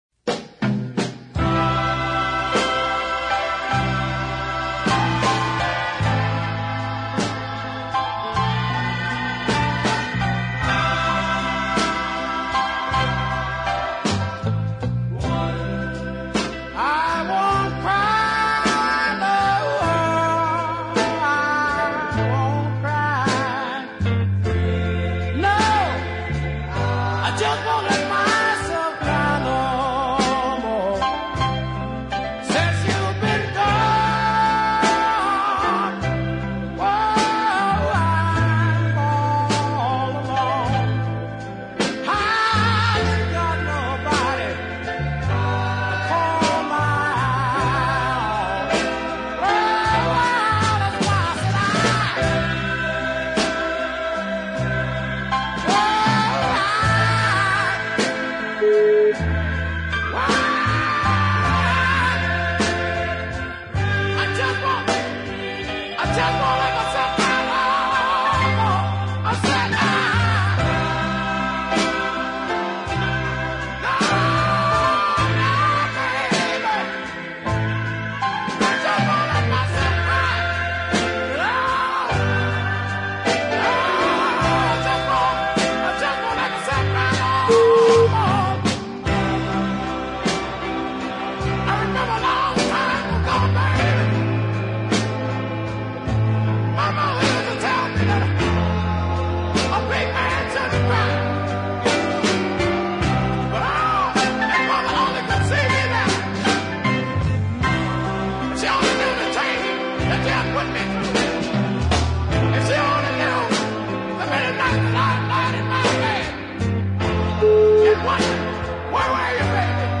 emotionally wracked